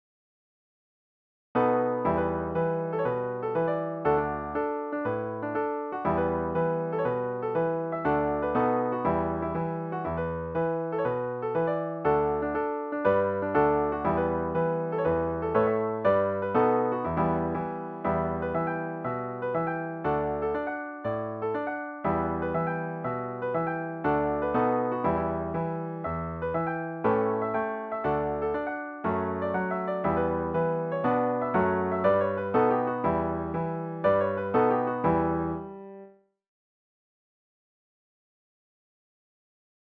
Traditional Tunes, Scottish Strathspey
DIGITAL SHEET MUSIC - PIANO ACCORDION SOLO